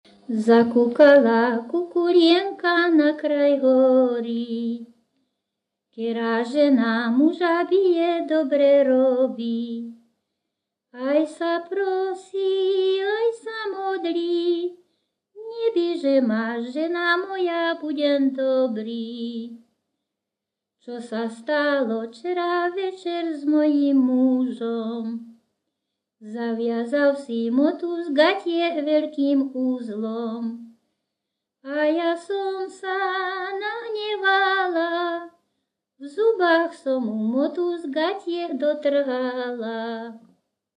Descripton sólo ženský spev bez hudobného sprievodu
Key words ľudová pieseň